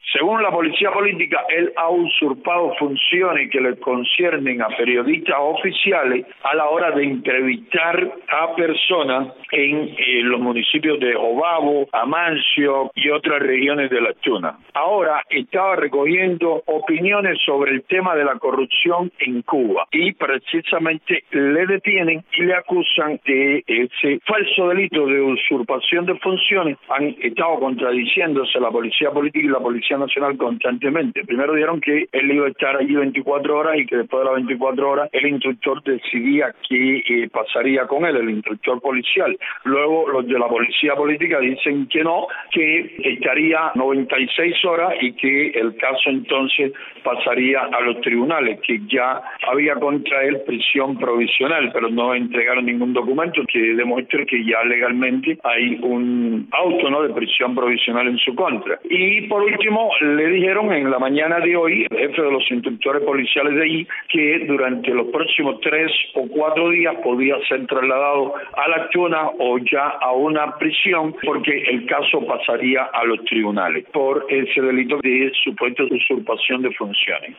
Declaraciones de Jose Daniel Ferrer